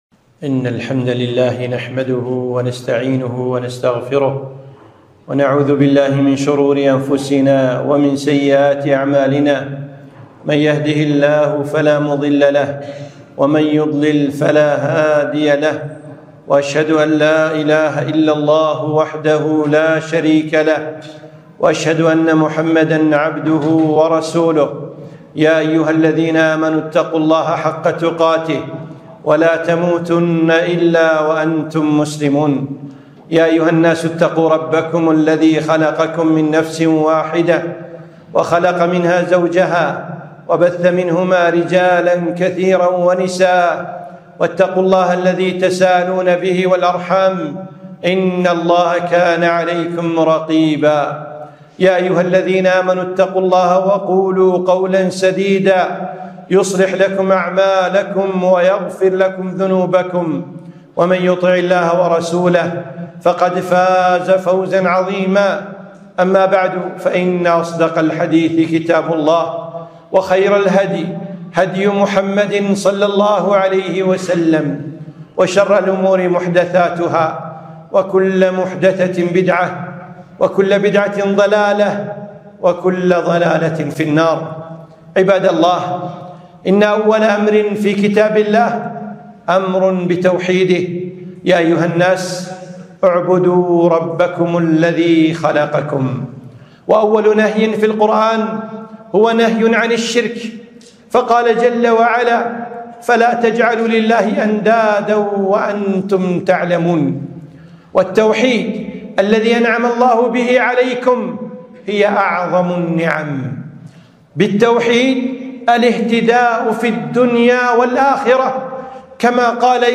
خطبة - نعمة التوحيد